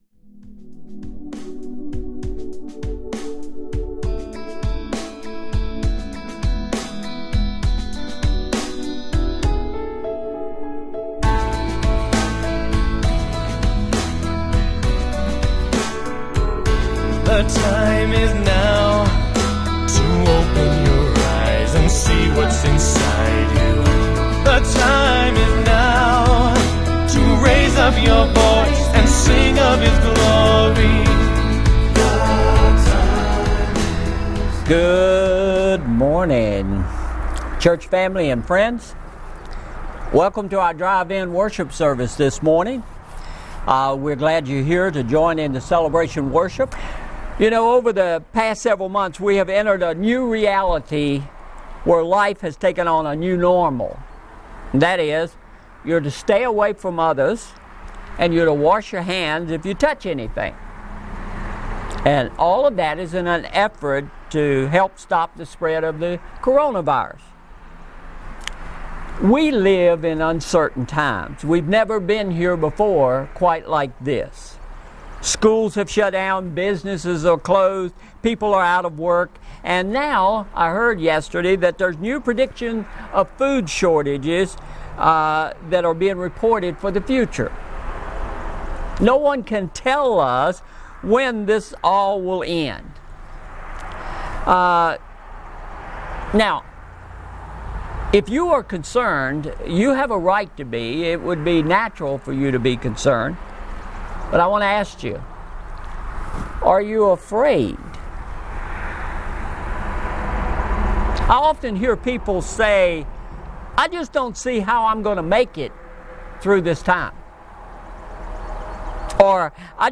MP3 Notes Discussion Sermons in this Series Sermon Series Lord, We Need A Miracle – Part 3 "Before We Lose Our Way!"